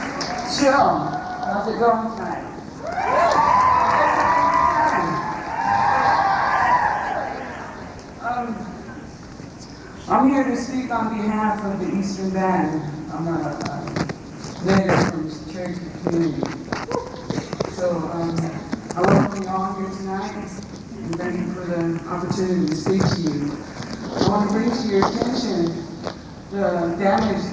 lifeblood: bootlegs: 1997-09-19: appalachian state student union - boone, north carolina
02. unknown speaker (0:27)